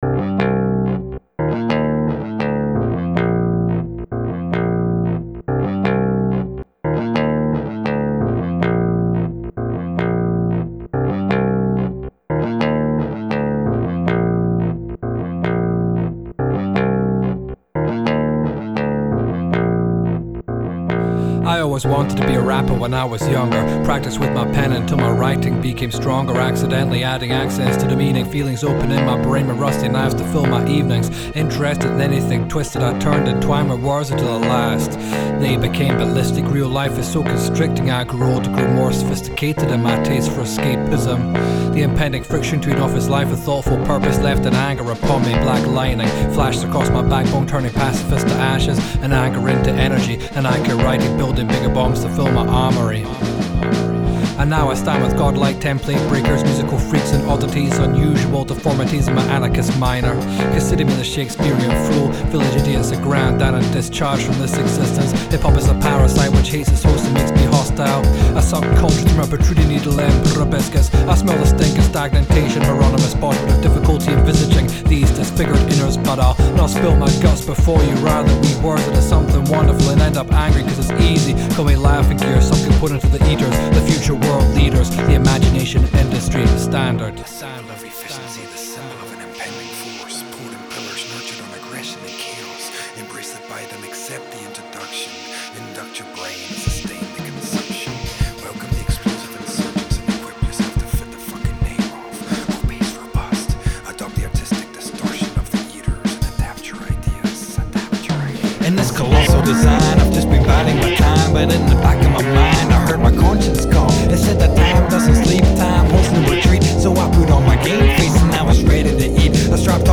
groupe de rap